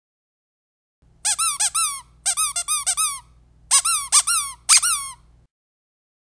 Squeaker Pillow Double-Voice – Large (12 Pack) – Trick
Double-voice squeakers make a sound both when they are pressed and when they are released.